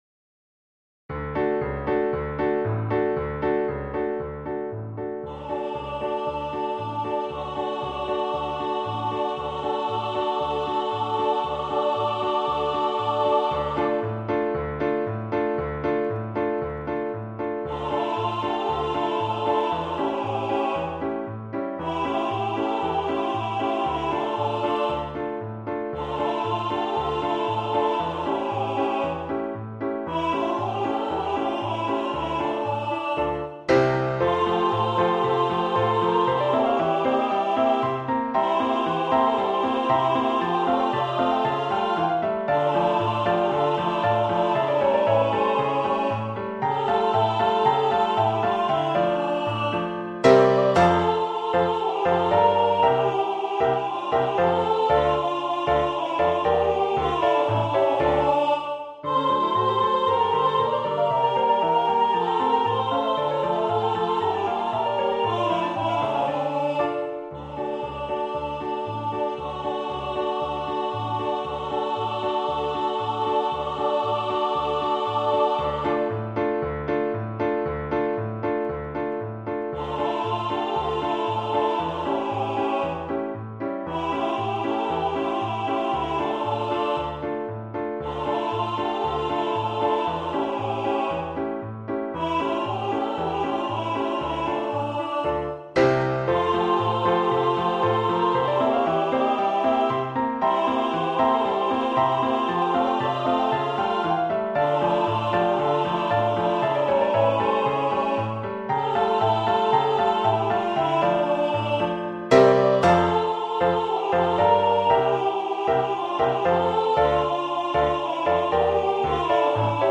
Ноты, хор, партитура голосов.
Один из вариантов исполнения: